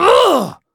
Kibera-Vox_Damage_02.wav